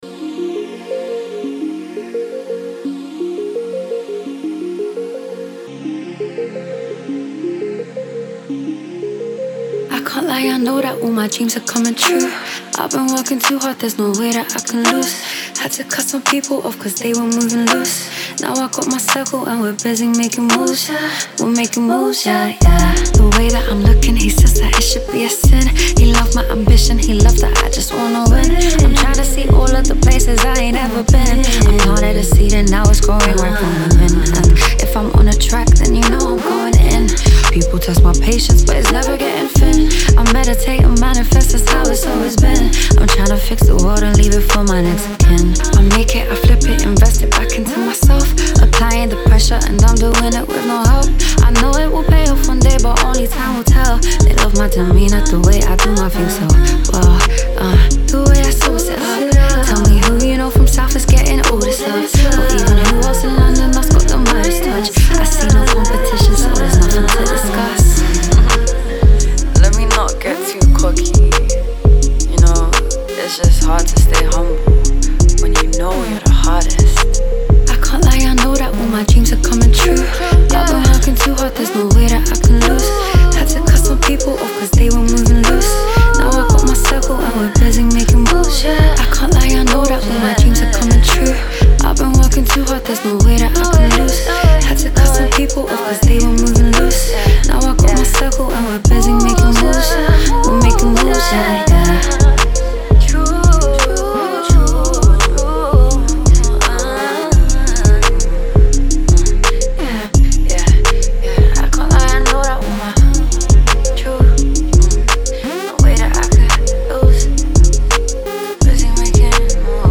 R&B/Pop